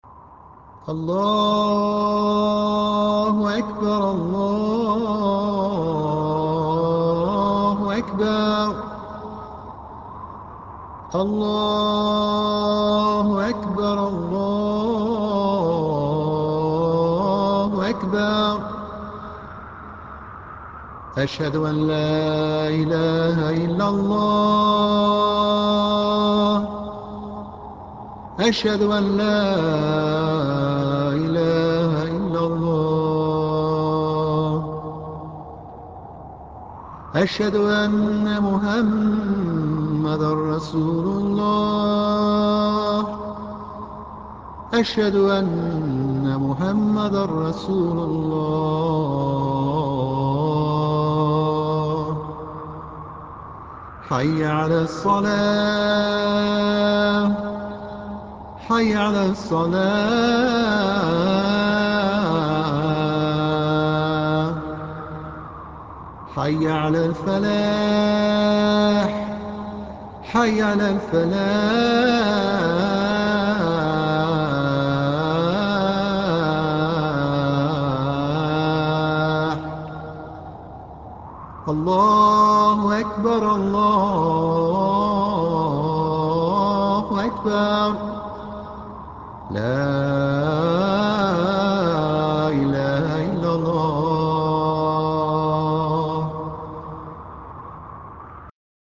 adhanyusufislam.ram